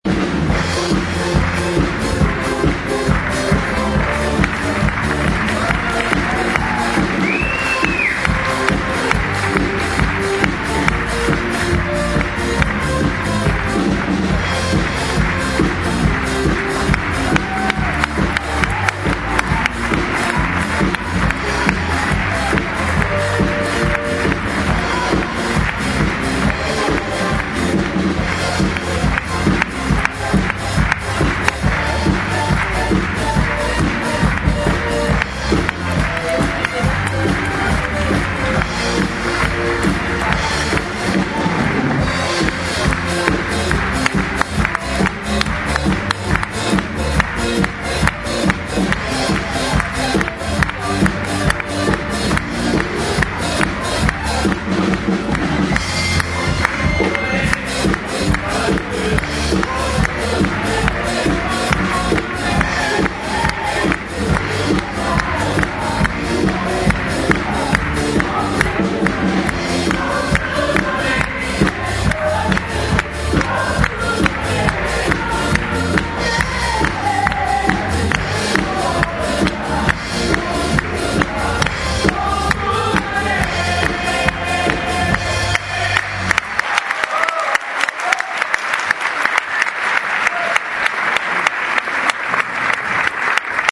20 Ocak 2012 – Cuma, ODTÜ – Kemal Kurdaş Salonu’nda;
bir sevgi müzikalinin final coşkusunu dinliyor
(Fantastik Müzikal)
Keman ve Klavye
Bateri
Piyano
Bas Gitar